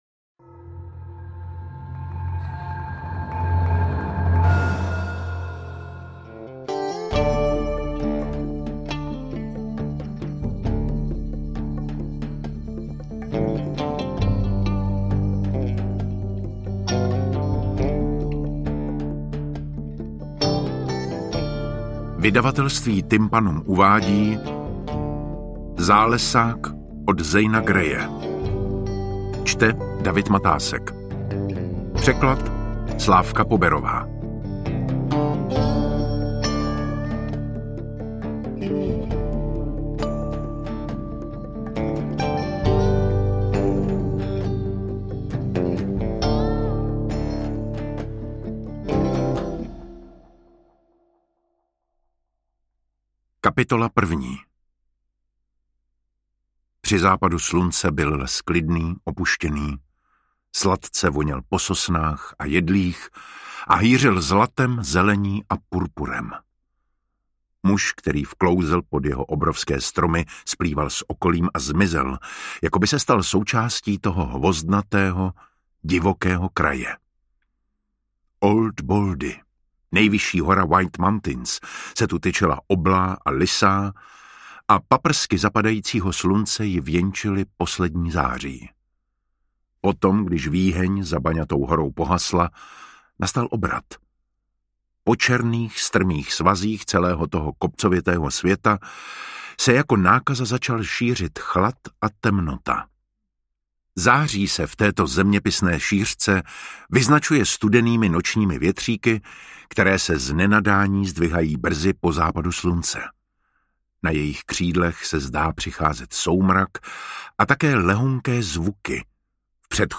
Interpret:  David Matásek
AudioKniha ke stažení, 38 x mp3, délka 14 hod. 14 min., velikost 782,9 MB, česky